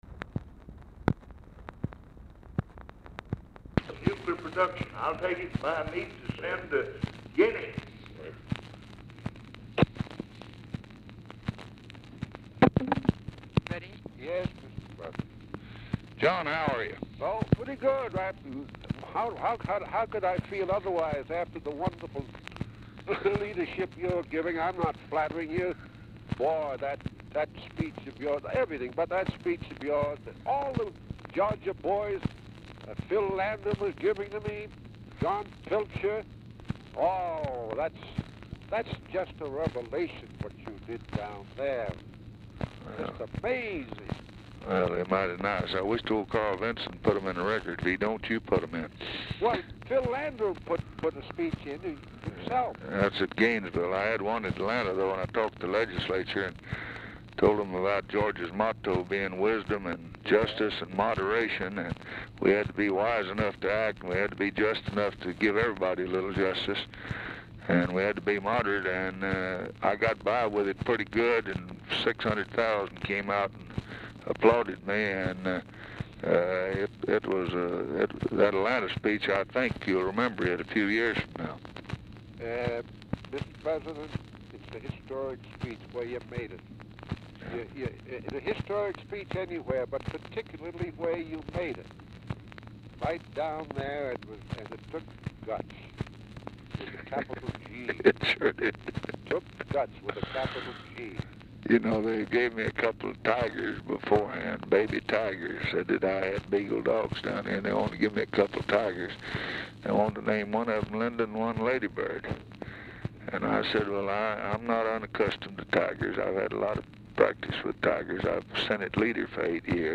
BRIEF OFFICE CONVERSATION PRECEDES CALL
Format Dictation belt
Specific Item Type Telephone conversation